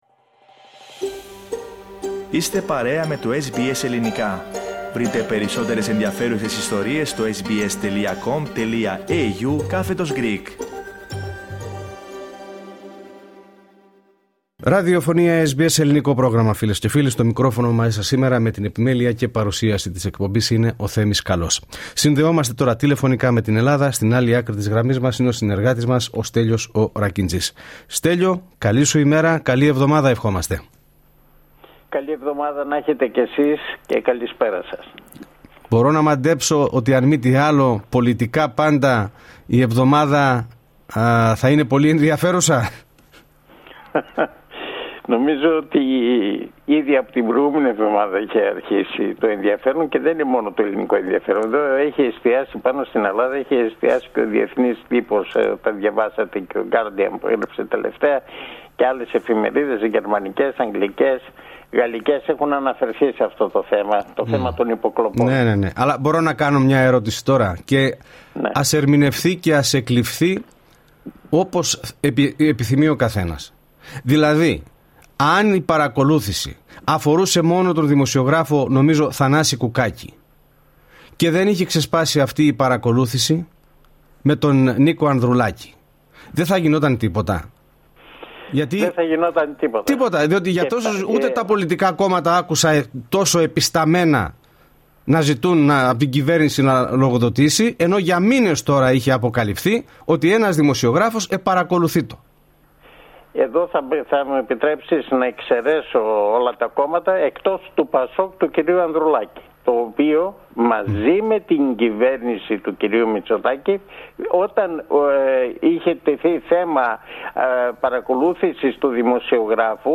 Η εβδομαδιαία ανταπόκριση από την Ελλάδα.